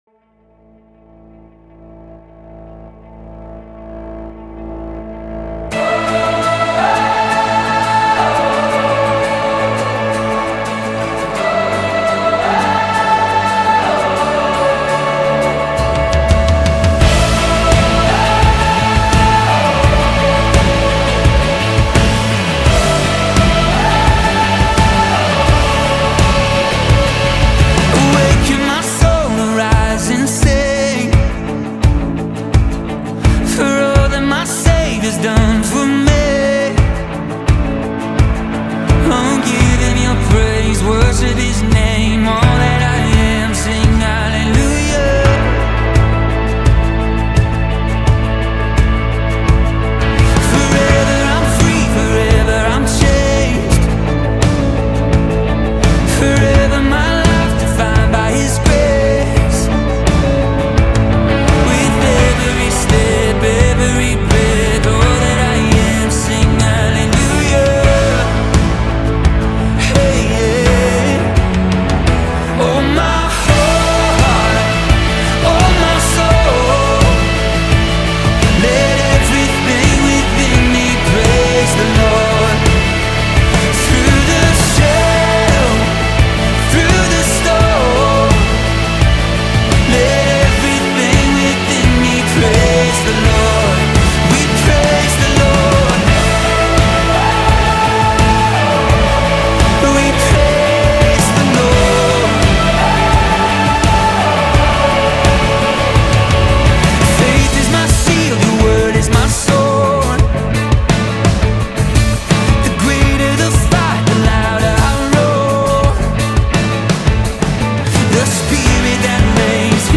песня
474 просмотра 533 прослушивания 24 скачивания BPM: 85